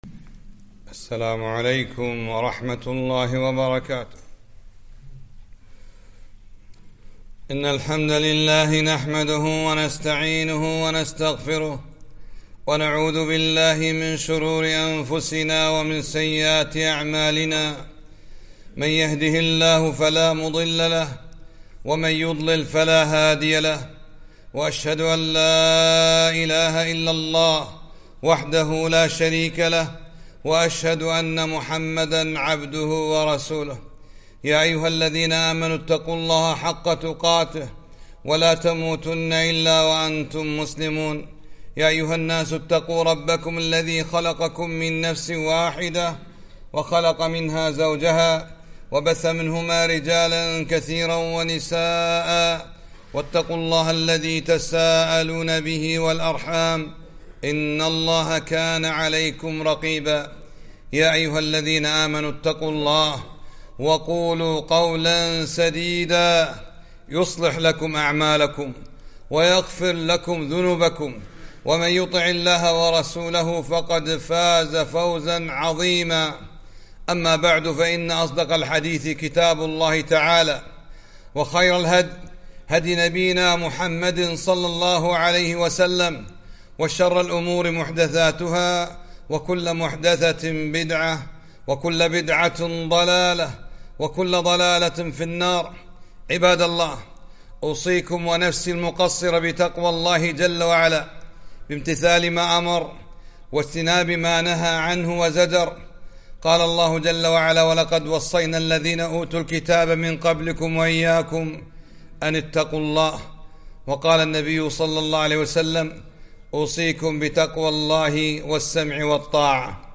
خطبة - لماذا نستغفر الله